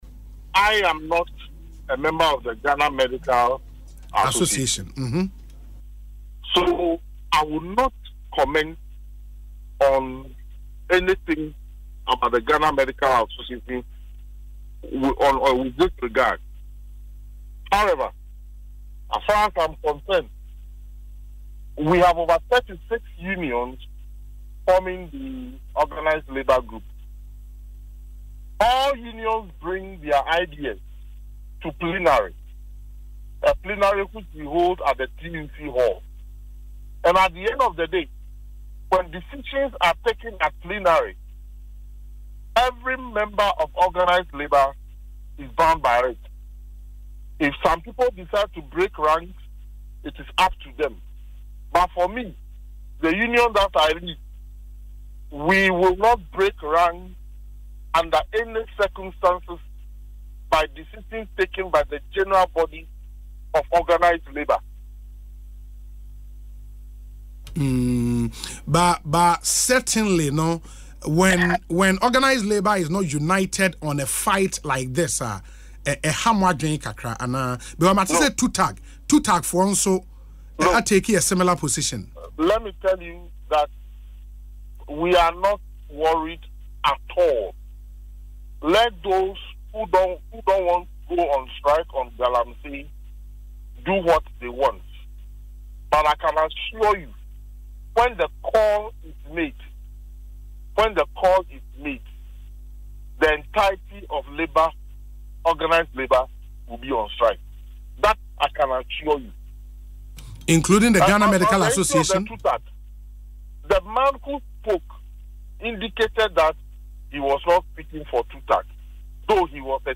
In an interview on Asempa FM Ekosii Sen programme Tuesday